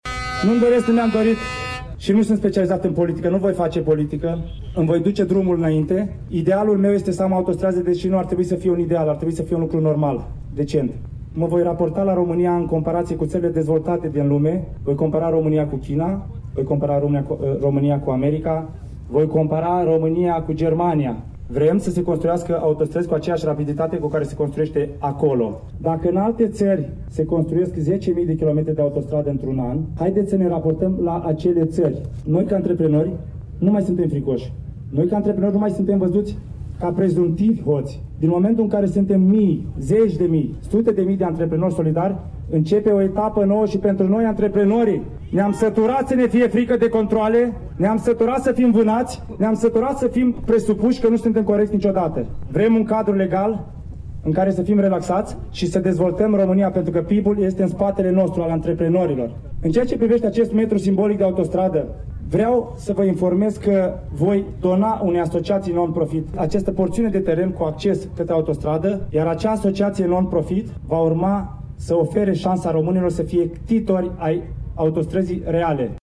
UPDATE – ora 15:30 În localitatea Cumpărătura, din judeţul Suceava, a fost inaugurat, la ora 15,00, primul metru de autostradă construit simbolic, în Moldova, de omul de afaceri Ştefan Mandachi.